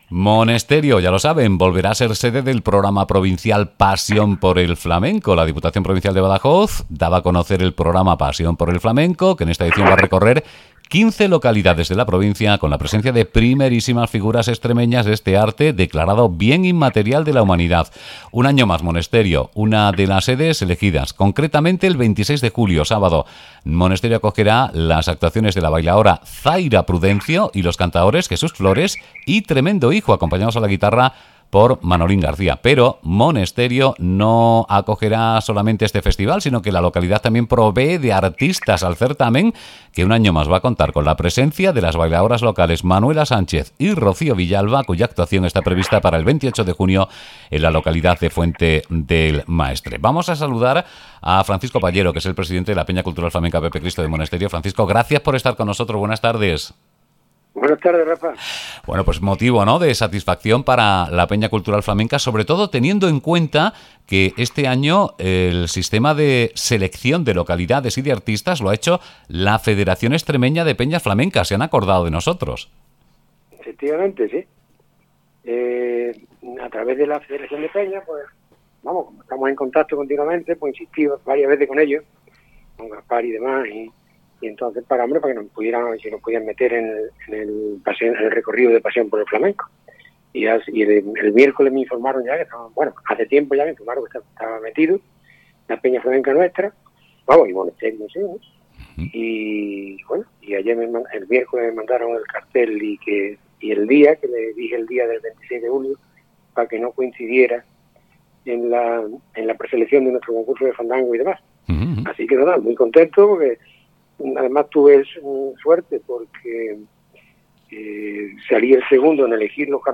VnzDsFLAMENCO6.mp3